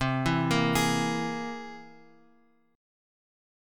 C Augmented 7th